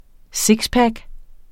Udtale [ ˈsigsˌpag ]